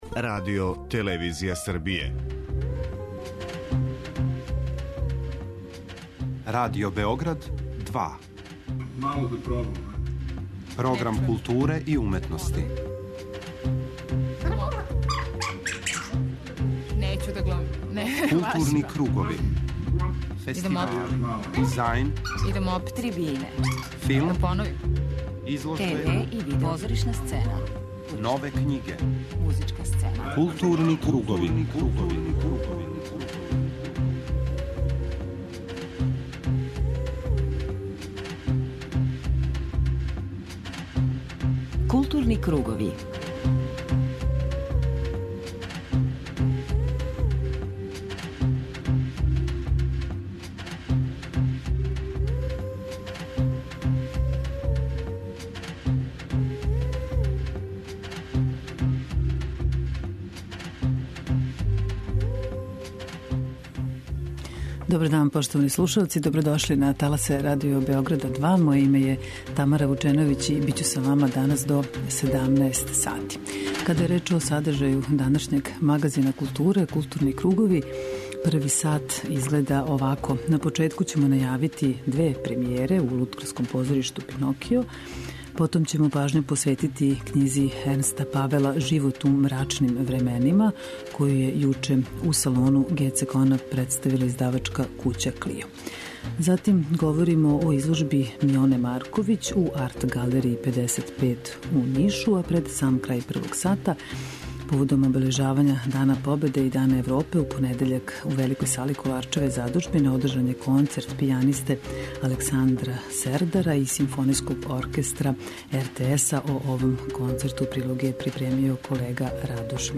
уживо са нама на таласима Радио Београда 2